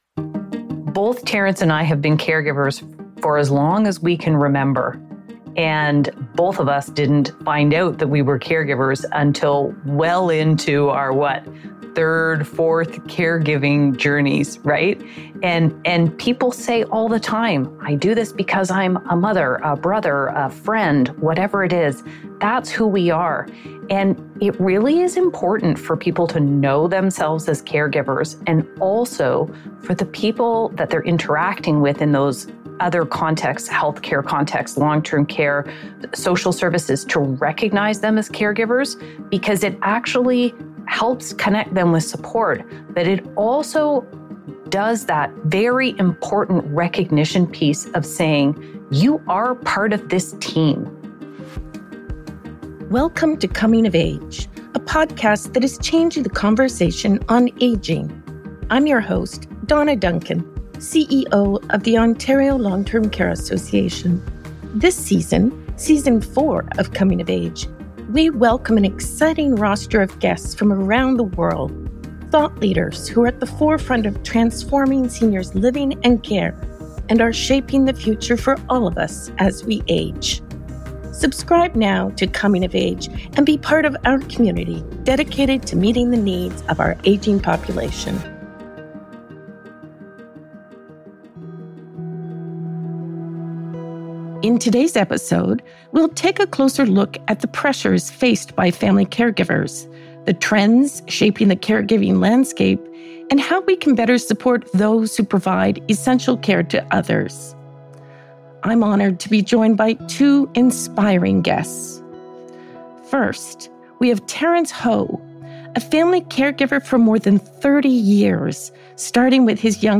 Together, they explore the evolving role of caregivers, the growing trends in caregiving responsibilities, and the crucial supports needed to ensure caregivers are not overwhelmed and neglected. Whether you're a caregiver yourself or connected to someone who is, this conversation sheds light on the importance of caring for those who care for others.